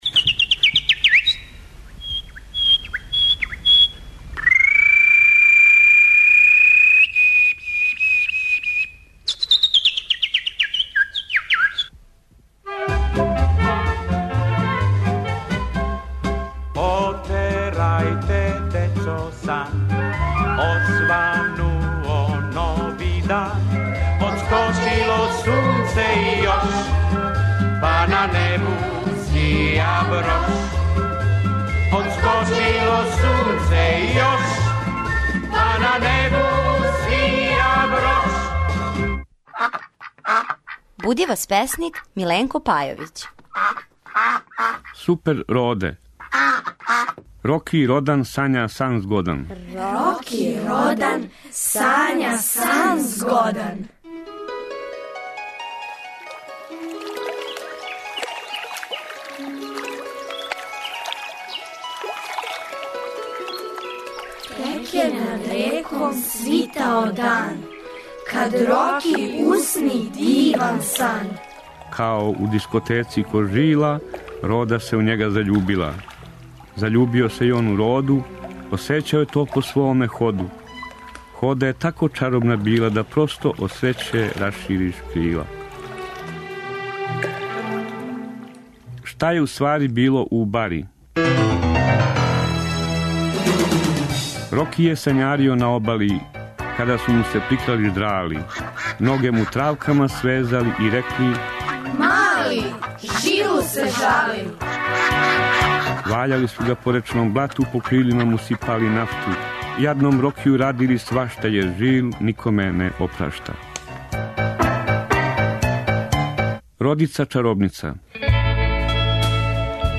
Прича за добро јутро